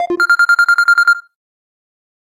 basic-bell_24965.mp3